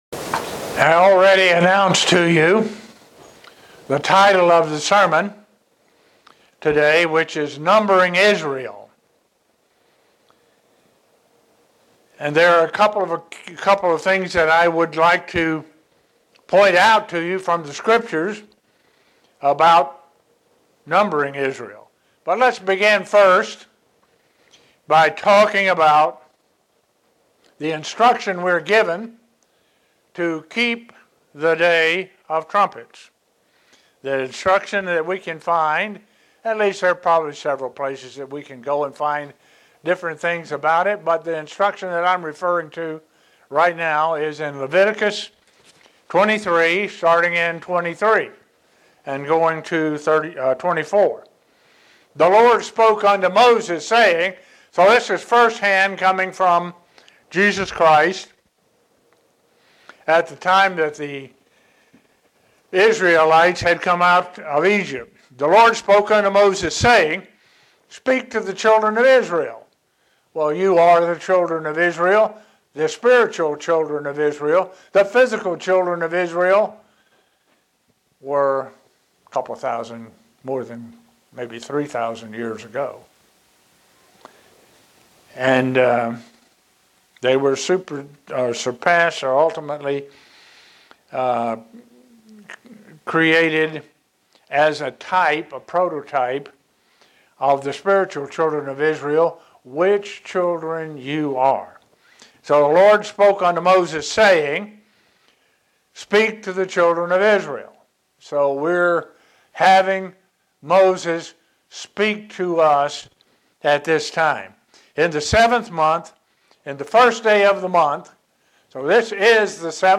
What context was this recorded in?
Given in Buffalo, NY Elmira, NY